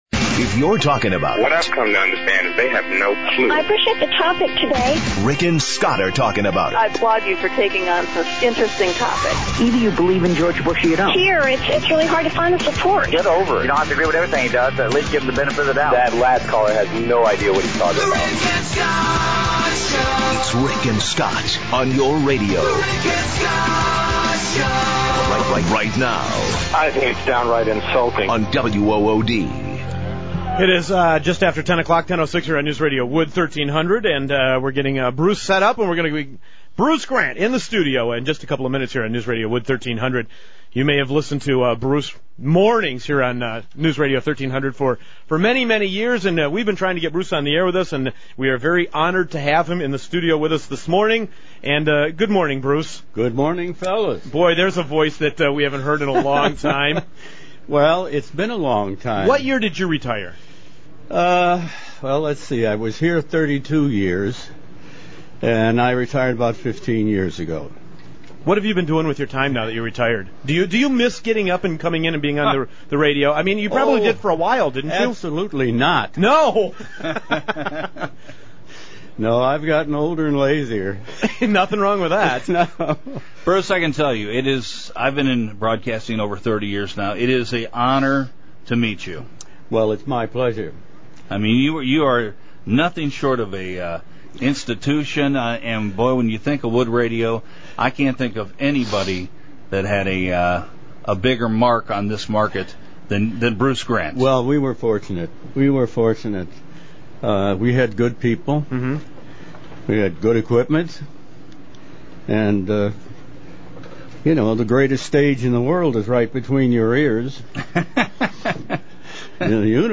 Let’s go back to 2006. Here is that interview…